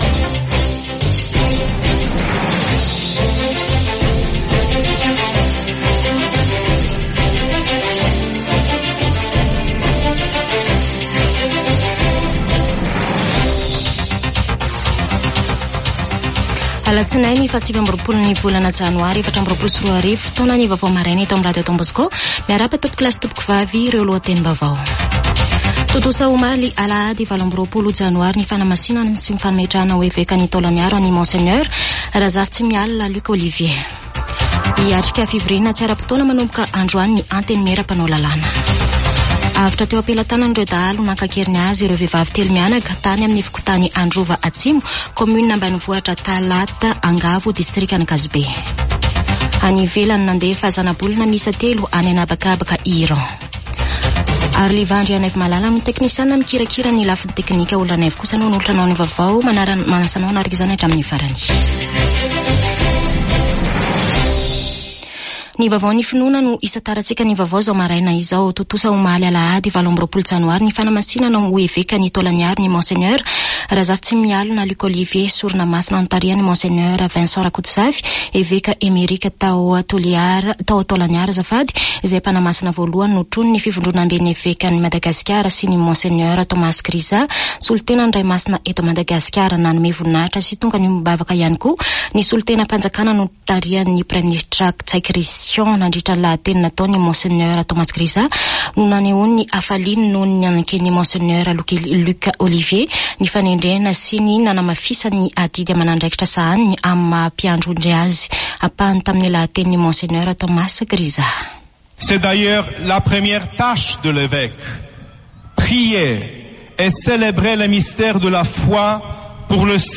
[Vaovao maraina] Alatsinainy 29 janoary 2024